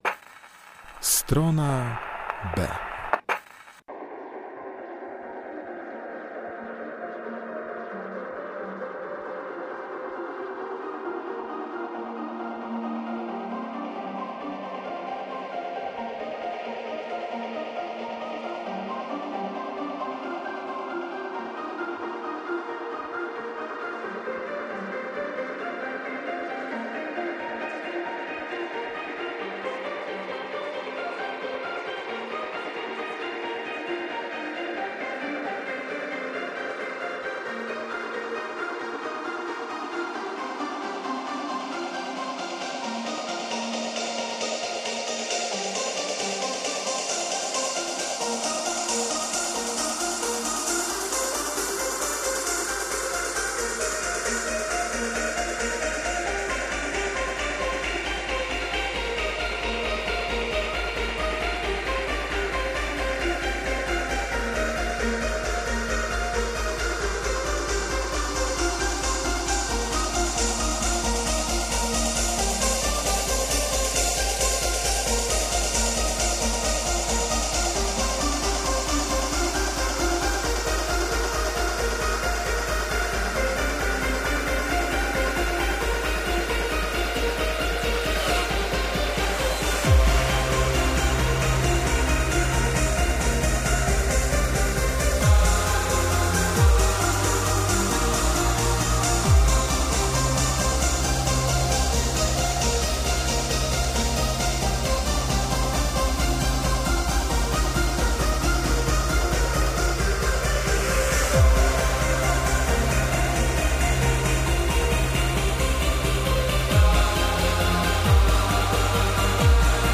W tym wydaniu audycji e-muzyka w klasycznym wydaniu.